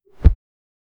Royalty-free athletic sound effects
Focus on capturing the strong exhalation and energetic breathing, as if air is forcefully rushing out of her lungs, conveying a sense of strength and determination. 0:01 Created Apr 16, 2025 1:23 PM Realistic human jump sound — soft foot push, slight “uh” breath, and gentle landing.
realistic-human-jump-soun-46npshqv.wav